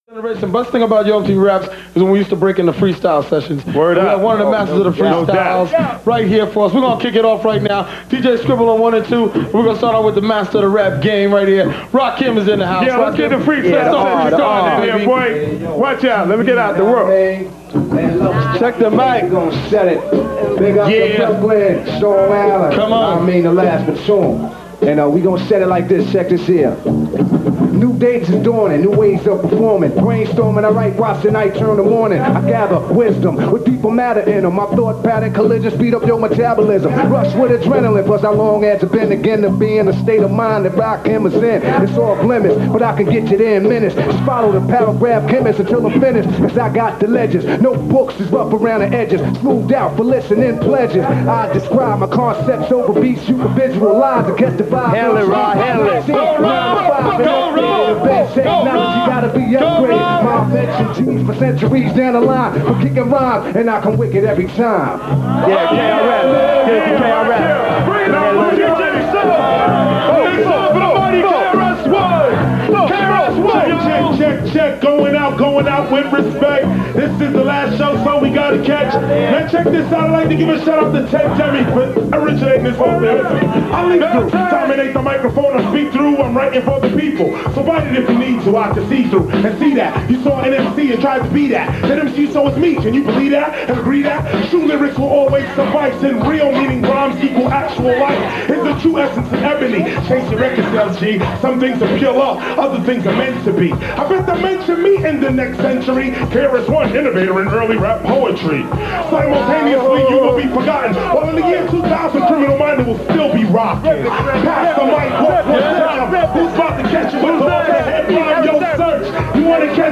Appel aux amateurs de RAP oldschool ;)
Je recherche le son du 1er morceaux, ça m'a l'air d’être une instru.. mais de quoi ?
Yo! MTV Raps   Last Episode Freestyle Part I Feat  Rakim, Krs One, Erick Sermon, Chubb Rock And Mc S